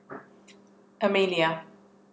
Amelia wakewords from 8 speakers of varying ages, genders and accents.